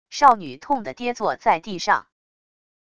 少女痛的跌坐在地上wav音频